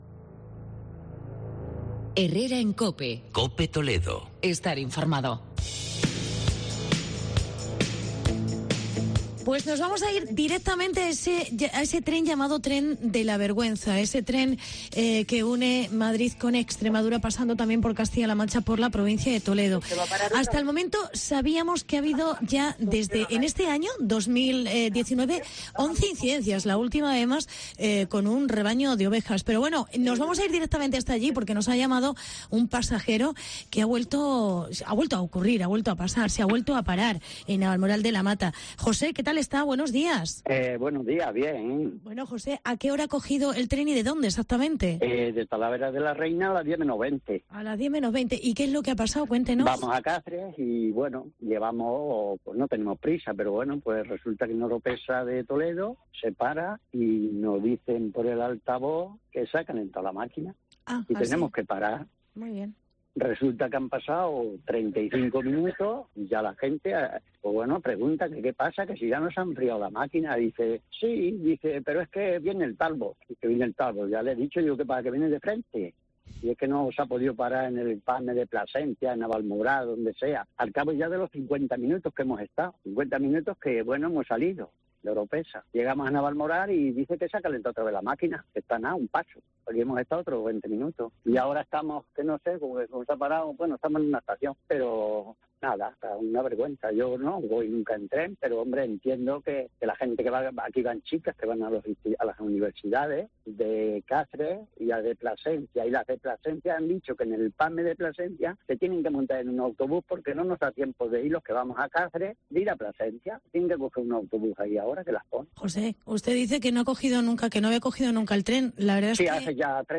AUDIO: Nueva avería en el "tren de la vergüenza". Testimonio en directo de un pasajero